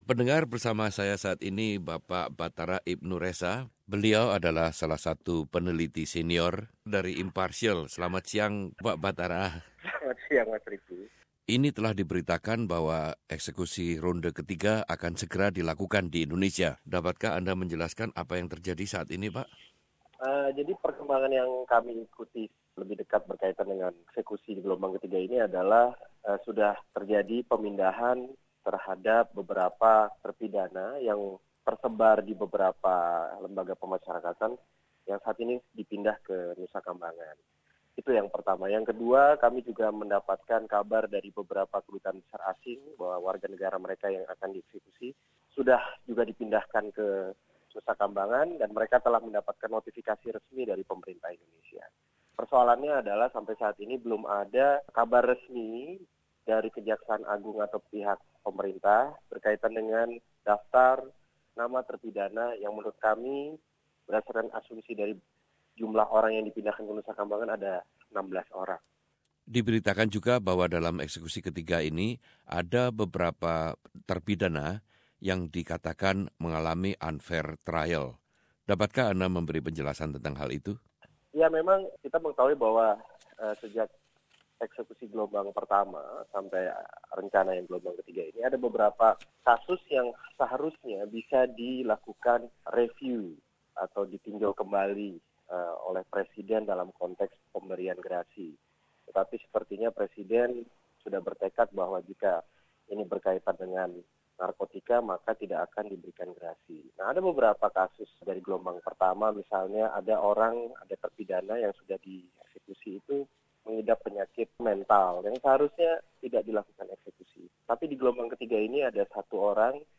Wawancara mengenai hukuman mati ronde ke 3 yang akan diselenggarkan oleh pemerintah Indonesia dalam waktu dekat ini